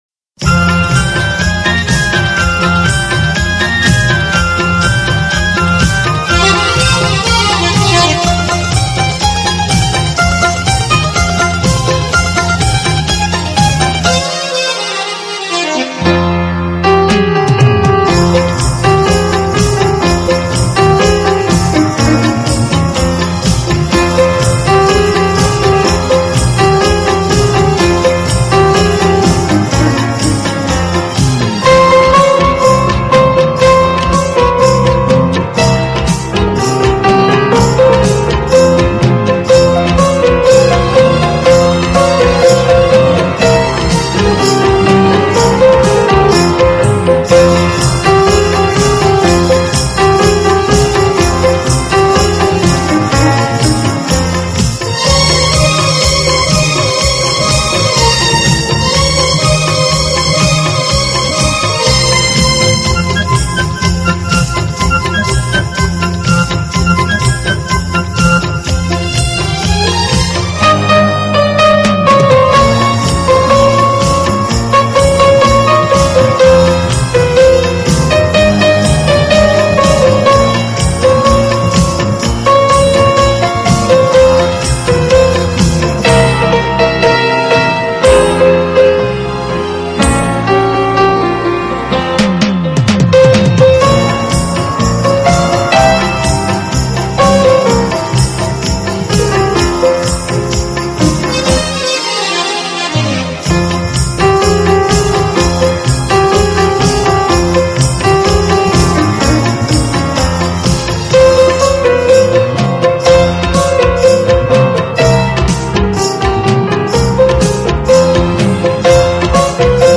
HomeMp3 Audio Songs > Instrumental Songs > Old Bollywood